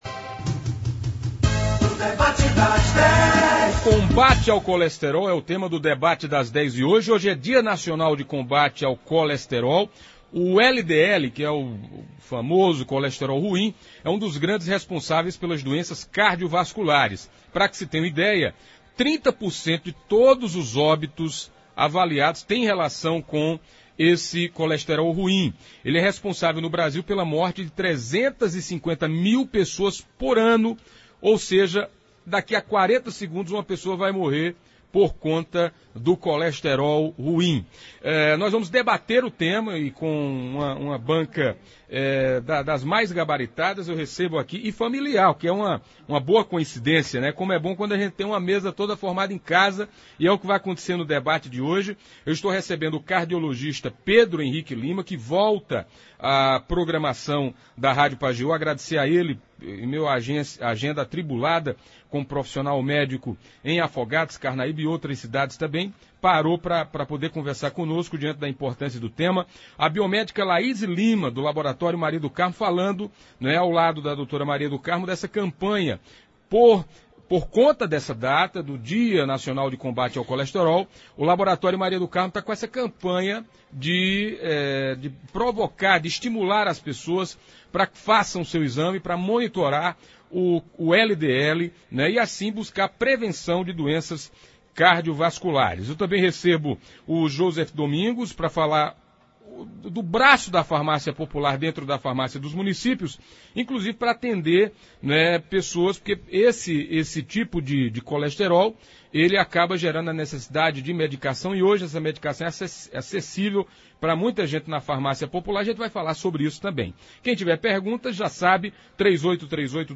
falaram sobre o combate o tema e tiraram dúvidas dos ouvintes e internauta da Pajeú.